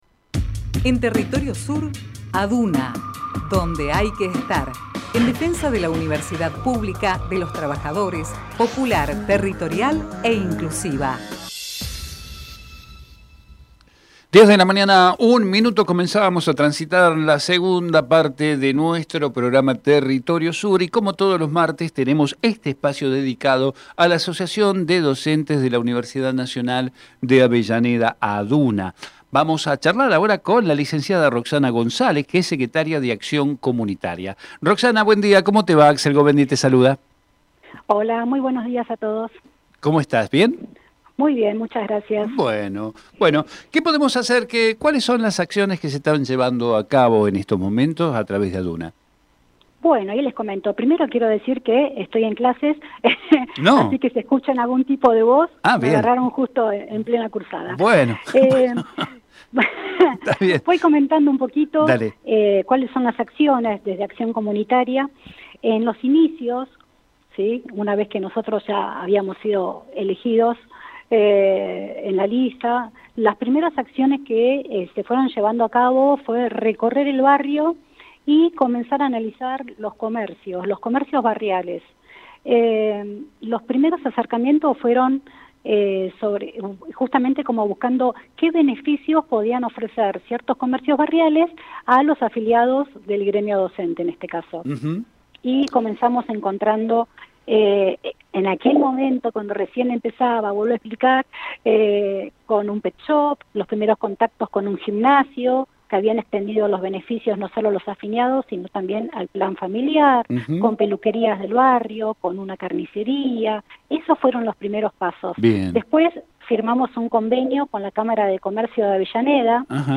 Compartimos enttrevista realizada en "Territorio Sur"